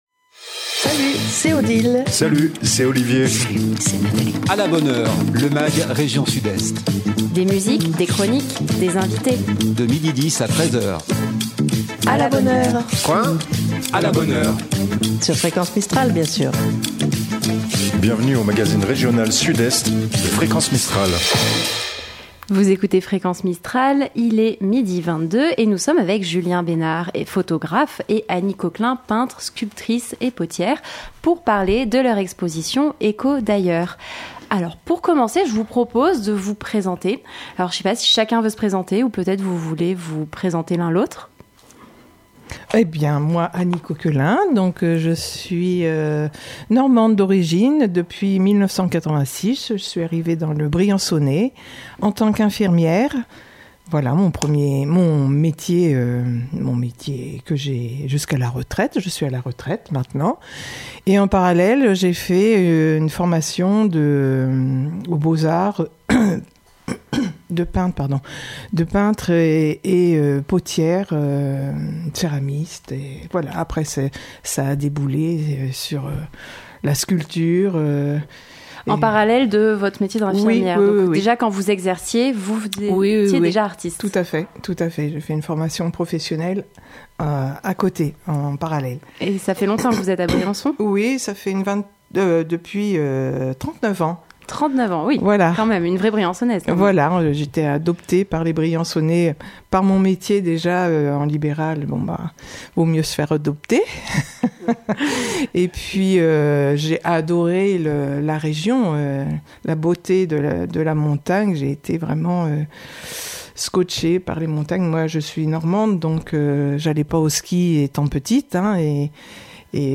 un Mag rien que pour vous, des invité.e.s en direct